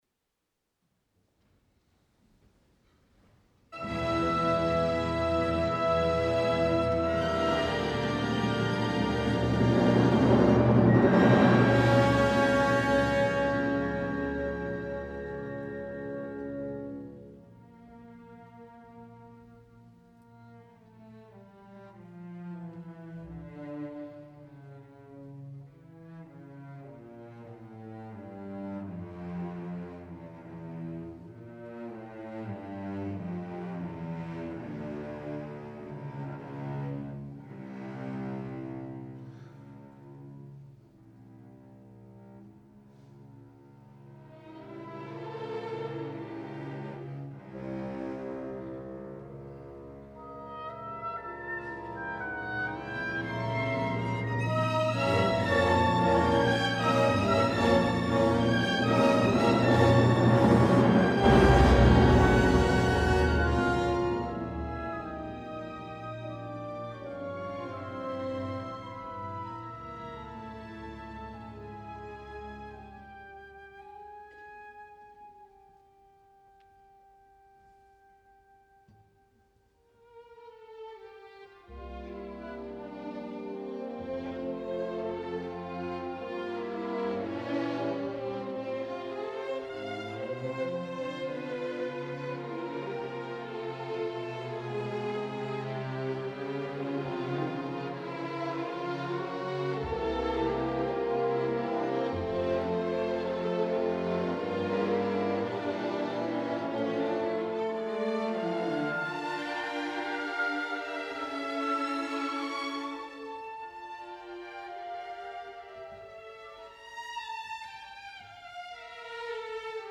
Tornem a Salzburg per escoltar la versió en forma de concert